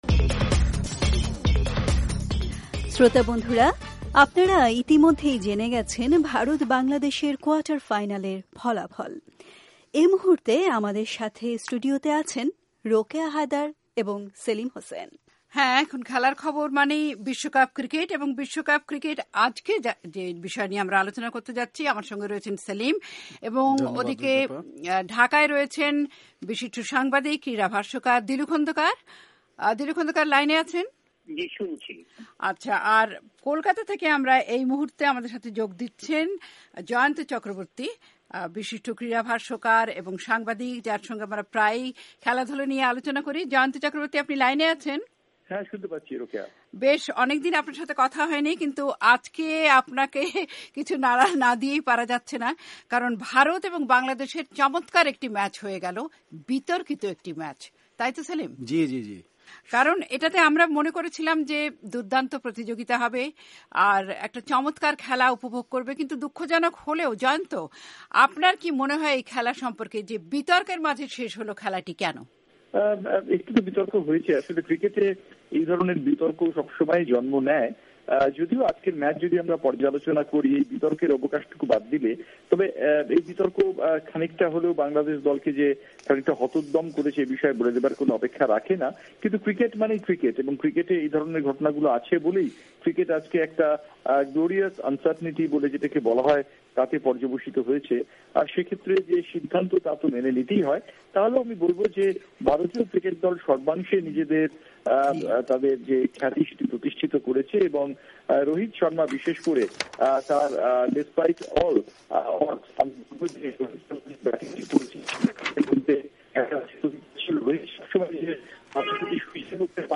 ওয়াশিংটন স্টুডিও থেকে